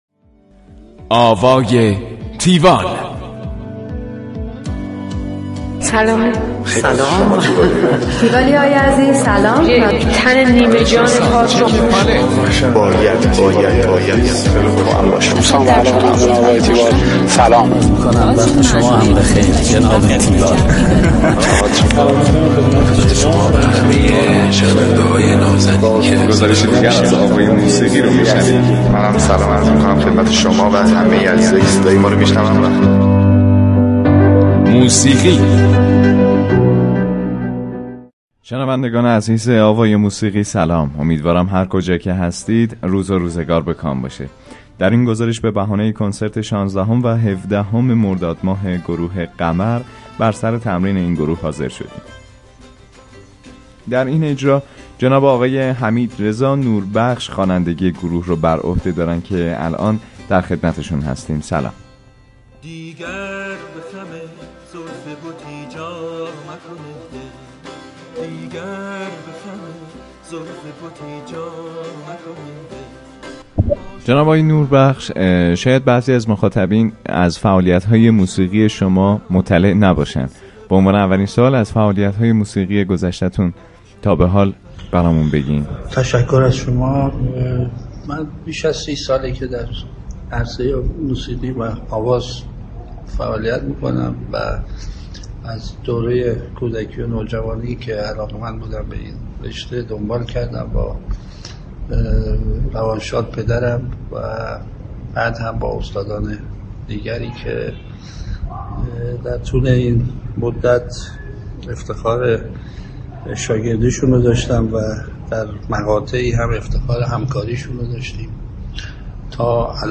گفتگوی تیوال با حمیدرضا نوربخش
tiwall-interview-hamidrezanorbakhsh.mp3